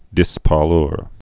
(dĭspär-lr)